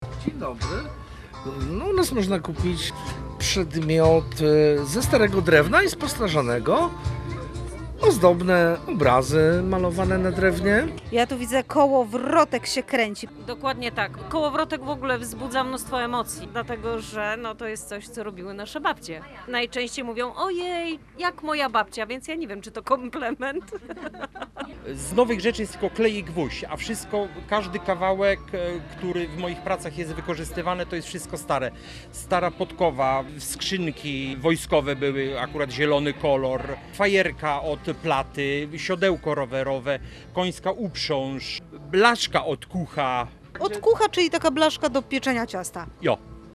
Posłuchaj rozmów naszej reporterki z wystawcami obecnymi na Dniach Kaszubskich w Gdańsku: